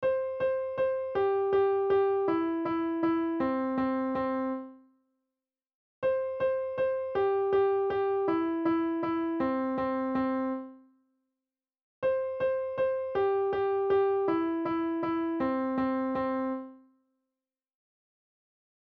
On the piano, play Row, Row Row Your Boat